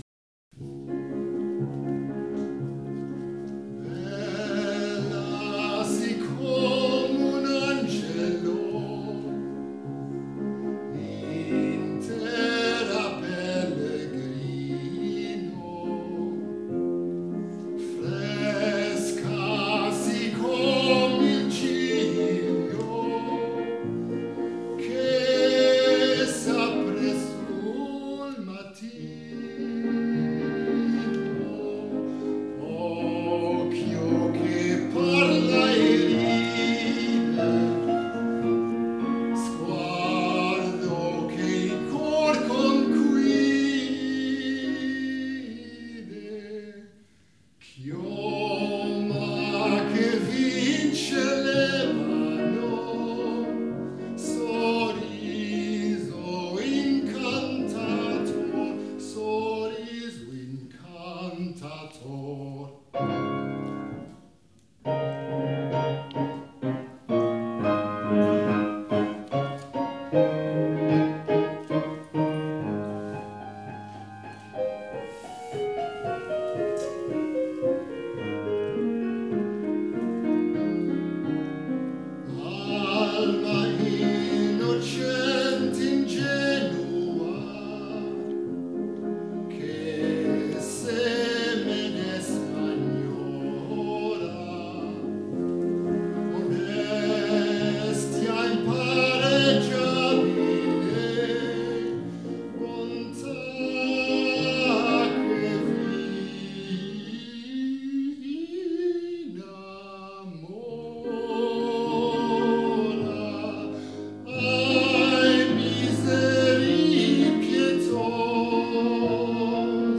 Hier kun je mijn zang beluisteren:
met pianobegeleiding